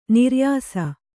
♪ niryāsa